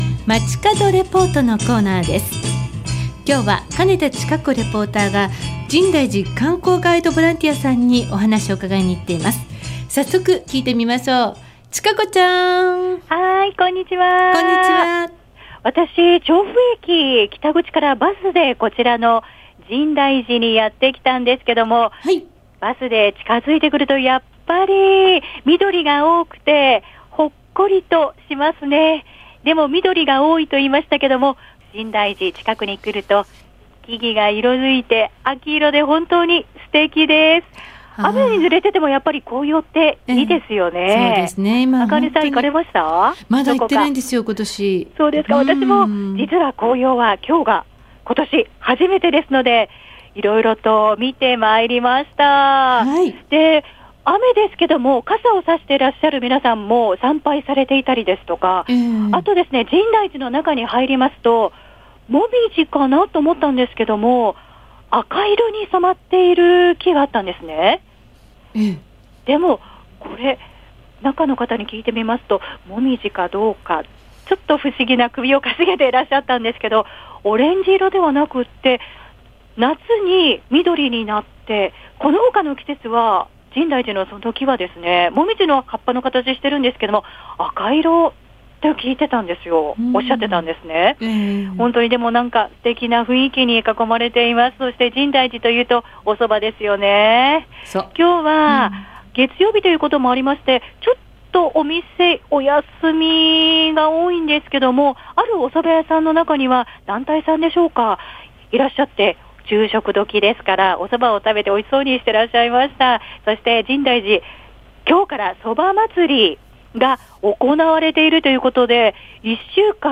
月曜日の街角レポート＜深大寺観光ボランティア・ガイド＞ 今日は、雨にも負けず♪新そばの季節に紅葉狩り！！